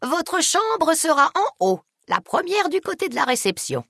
Jeannie May Crawford indiquant sa chambre au joueur dans Fallout: New Vegas.
Dialogue audio de Fallout: New Vegas